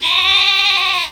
Cri de Moumouton dans Pokémon Épée et Bouclier.